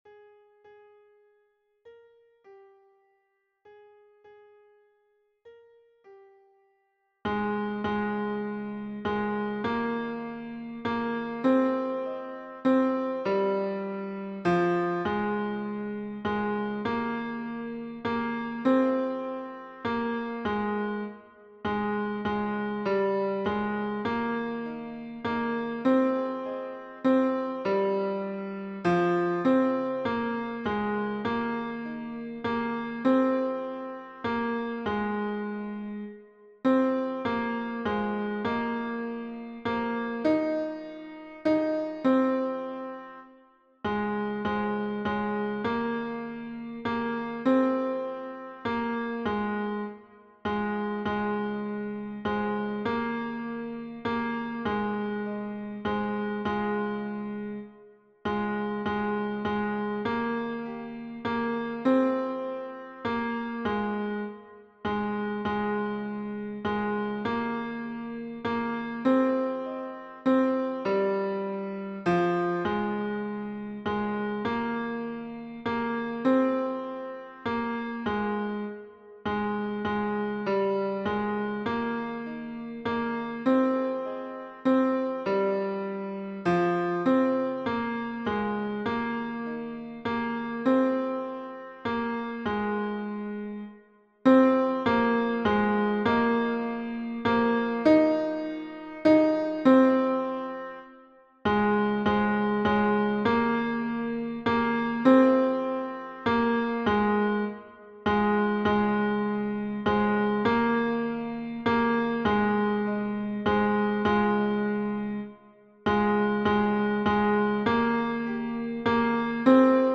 - Œuvre pour chœur à 4 voix mixtes (SATB) + 1 voix soliste
Tenor Version Piano